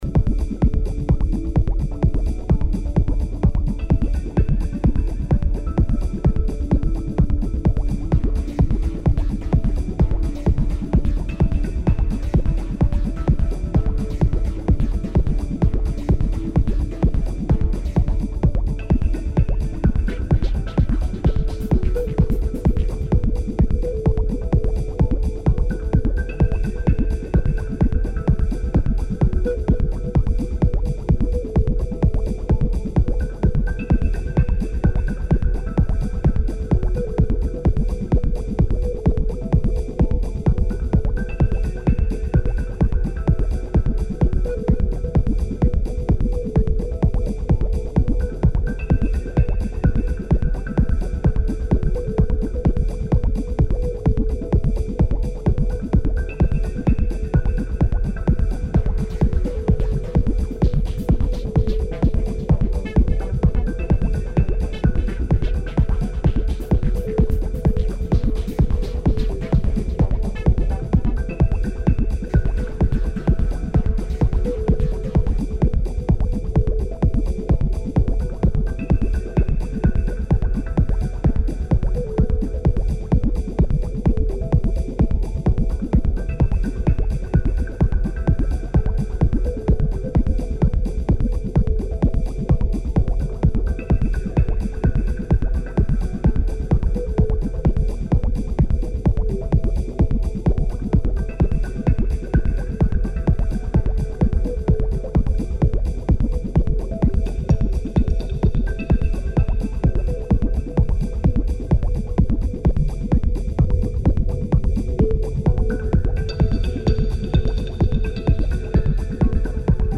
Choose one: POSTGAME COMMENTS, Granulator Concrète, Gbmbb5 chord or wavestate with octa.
wavestate with octa